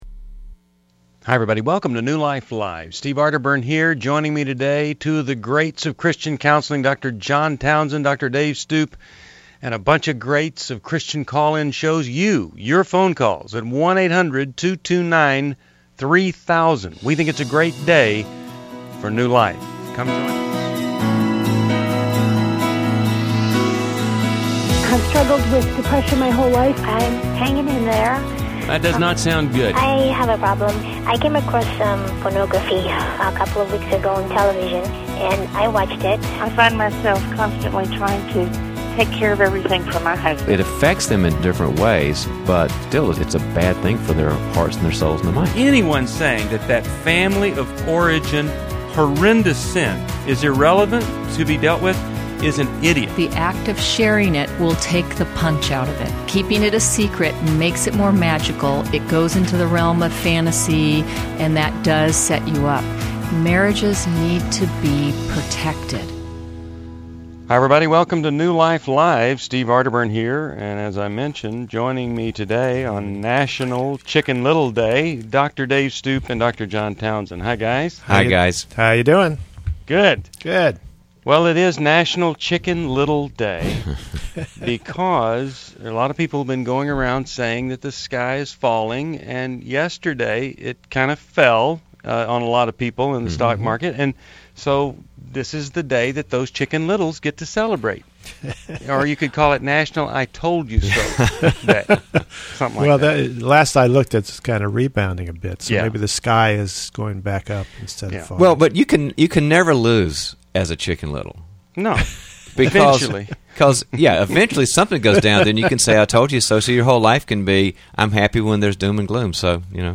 Explore relationship challenges in New Life Live: August 9, 2011, as hosts tackle separation, mental health, and parenting amid divorce concerns.
Caller Questions: 1.